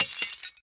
sfx_break.ogg